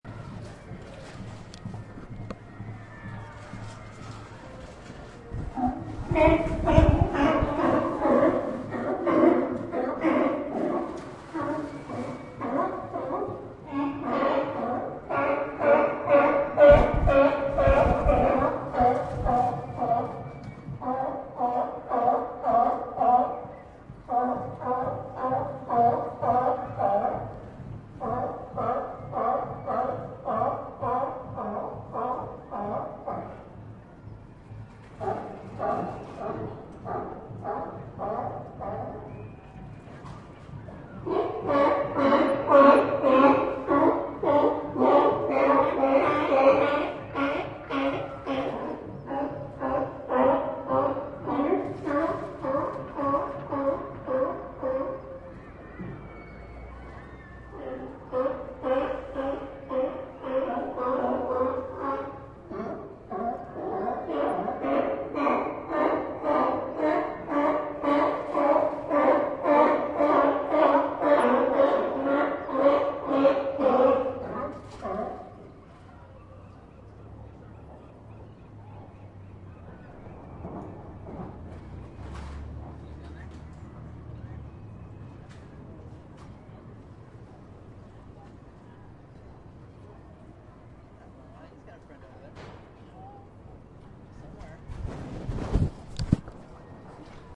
Sea Lions In Santa Cruz Bouton sonore